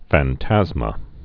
(făn-tăzmə)